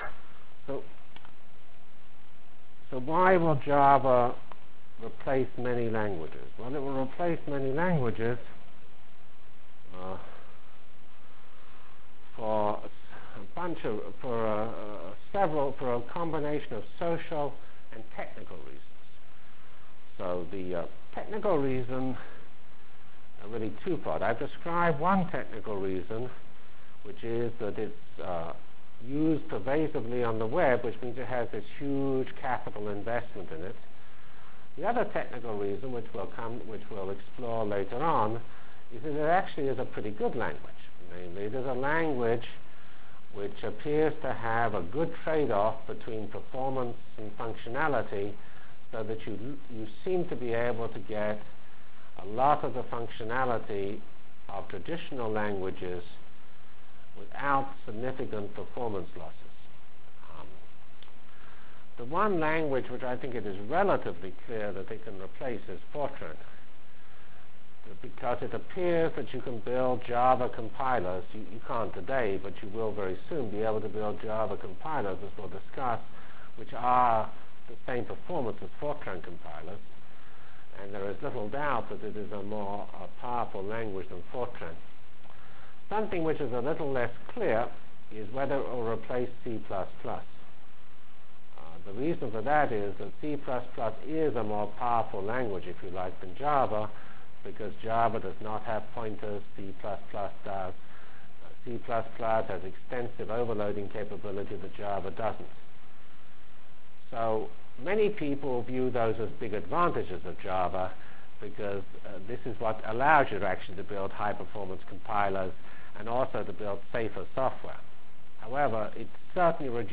From Feb 5 Delivered Lecture for Course CPS616